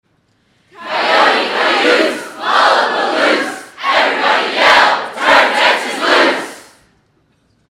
The Big Yell! – Recording old UT cheers from the 1890s – 1900s.
cayote-cayuse-yell.mp3